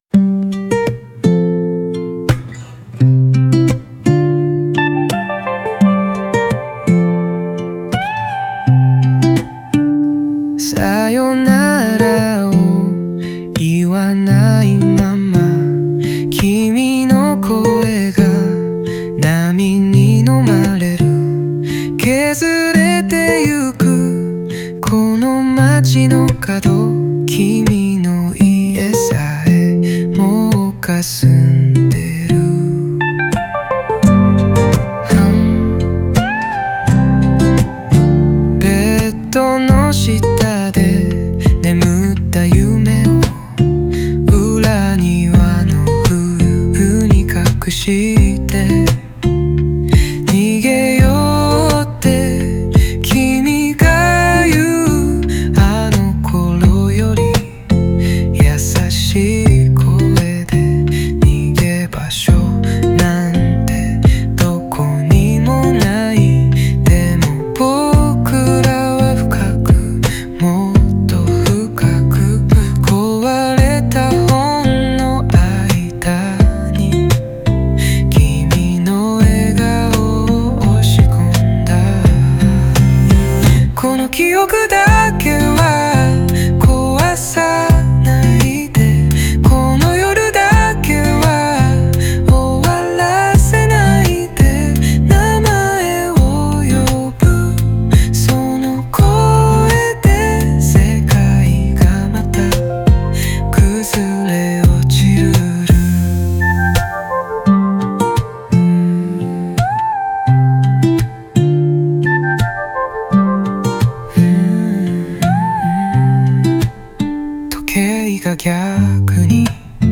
静かな始まりから徐々に熱を帯び、クライマックスでは激情が爆発する構成。